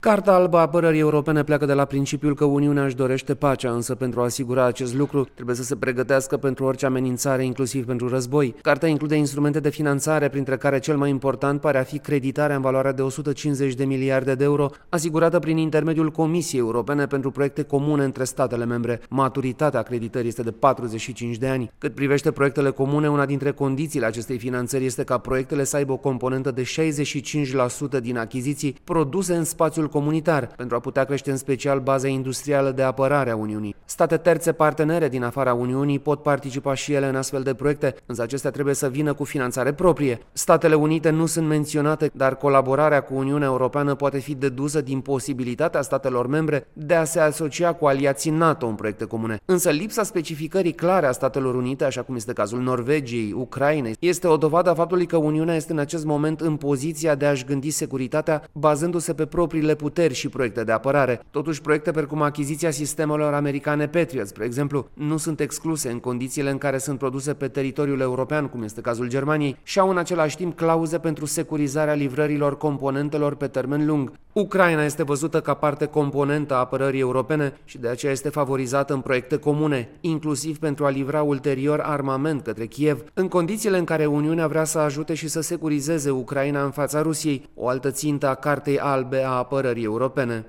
Corespondentul RRA